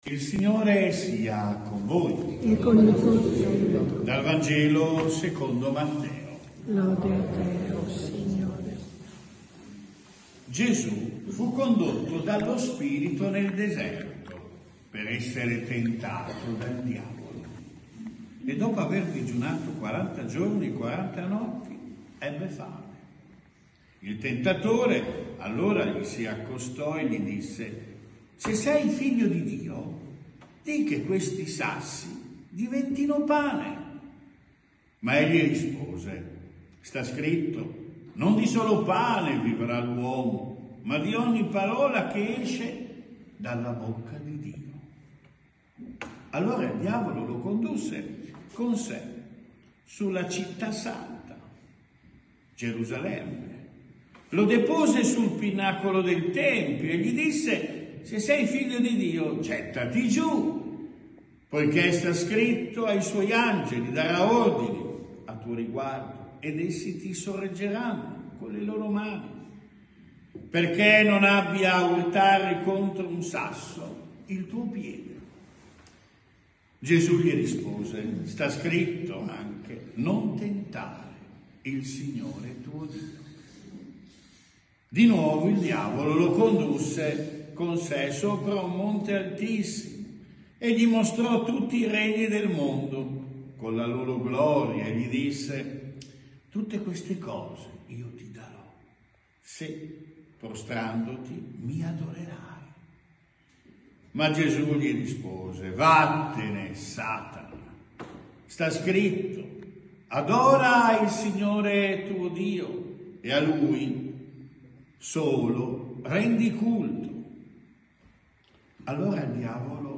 Omelia I quar. Anno A – Parrocchia San Pellegrino